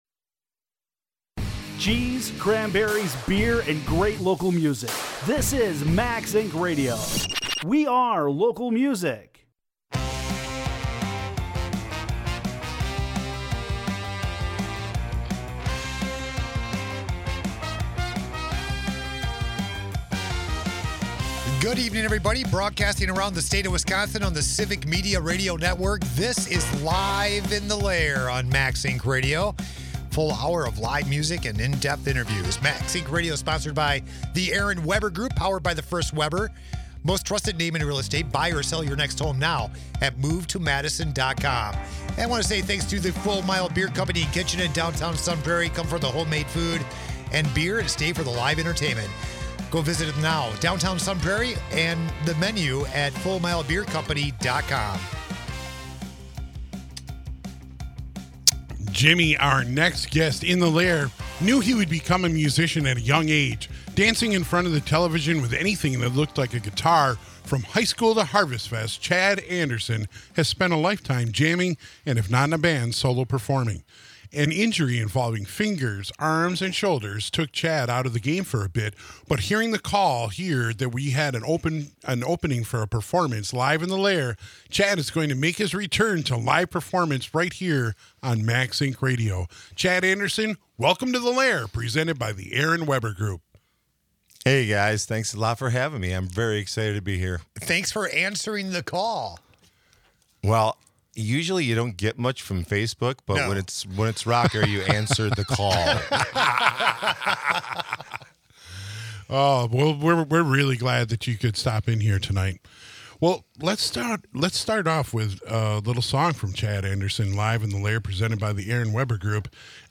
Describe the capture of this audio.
Live in the Lair on Max Ink Radio